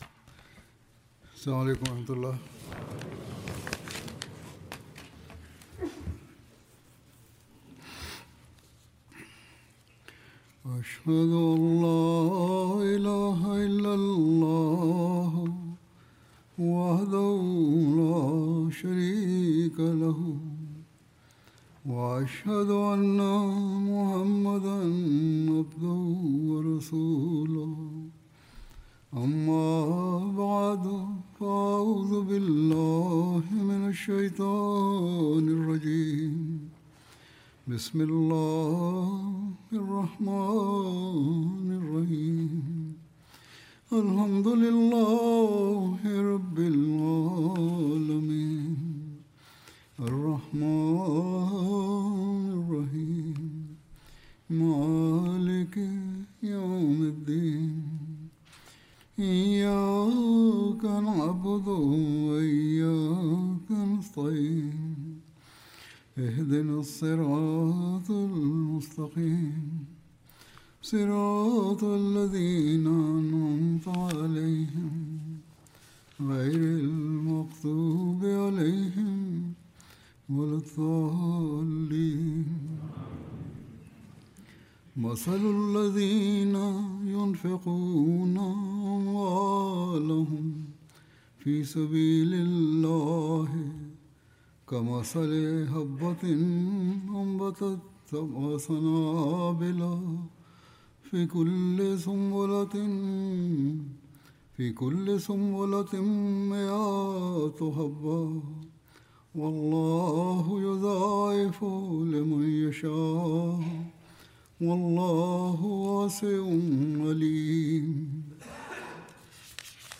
Indonesian translation of Friday Sermon delivered by Khalifa-tul-Masih on October 4th, 2024 (audio)